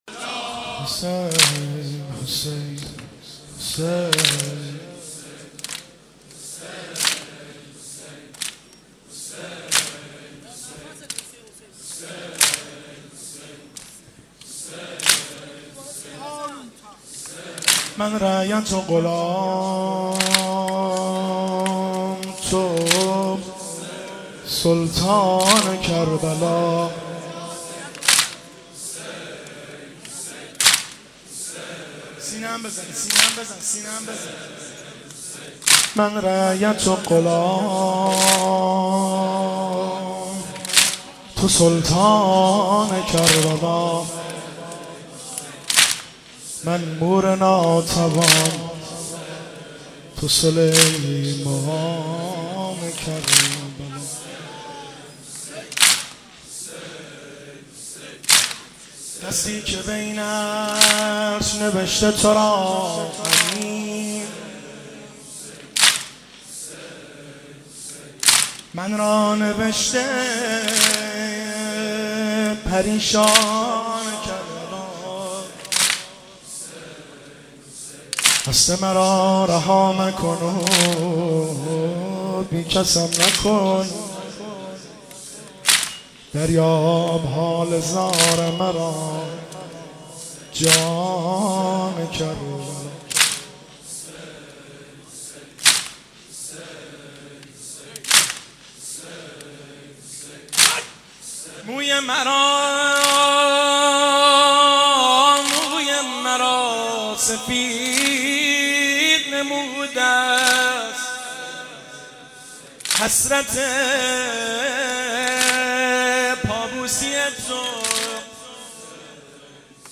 مداح
مناسبت : اربعین حسینی